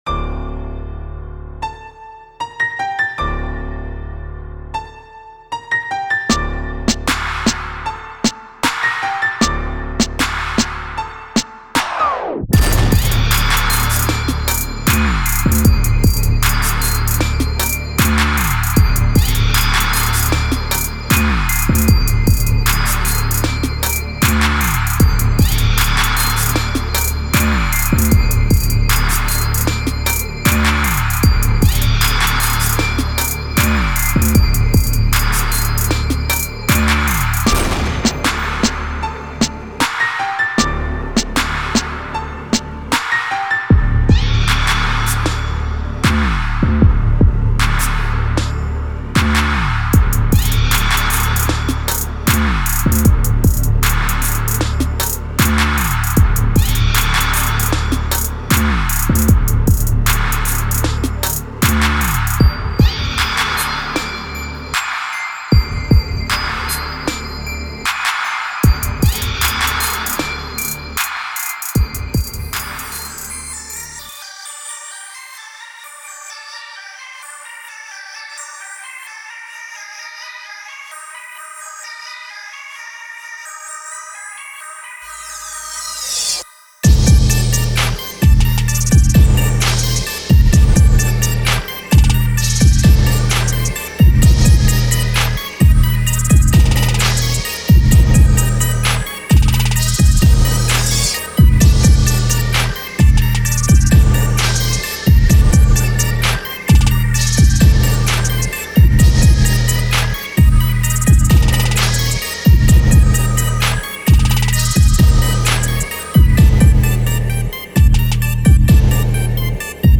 • 5 Construction Kits